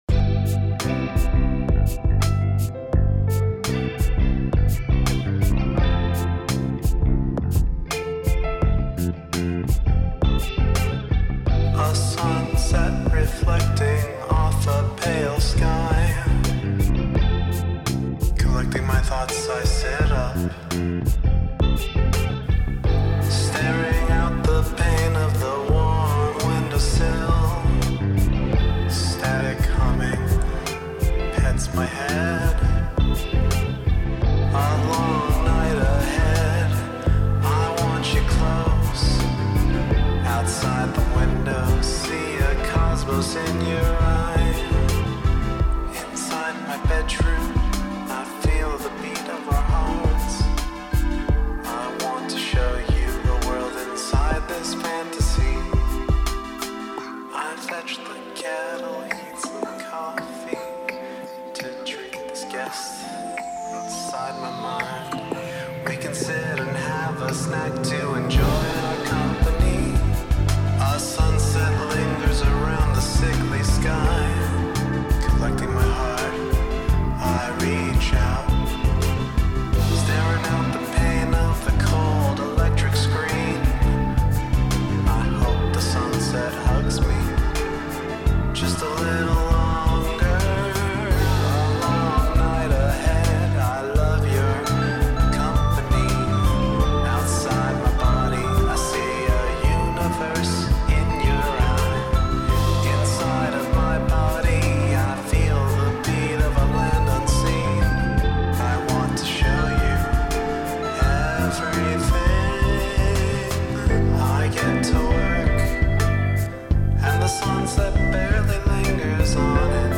Wow, what a great 2-step from 33:53